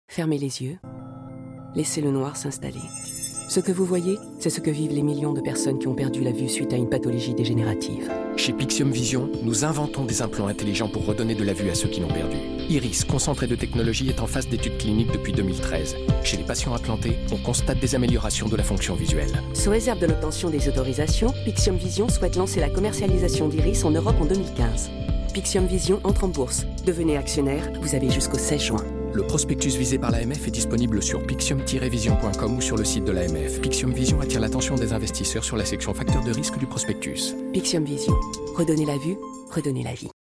Une campagne globale, déclinée en campagne presse, radio et digital qui concentre le message sur l’interpellation de la zone concernée et qui nous parle à tous.
Pixium_Vision_40s_spot_de_f_.mp3